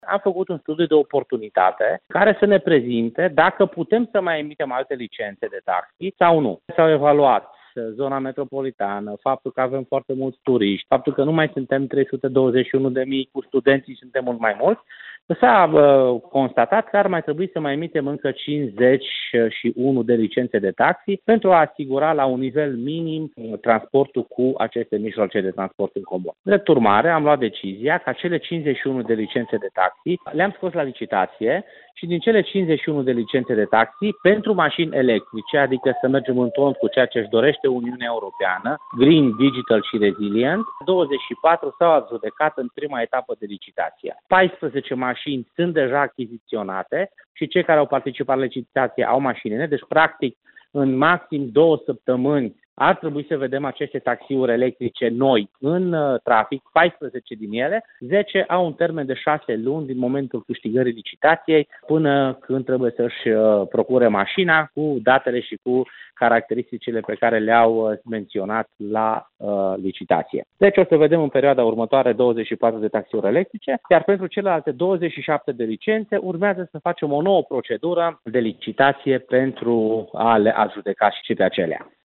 Declarațiile sale, în format AUDIO, mai jos:
Dan-Tarcea-taxiuri-electrice-Cluj.mp3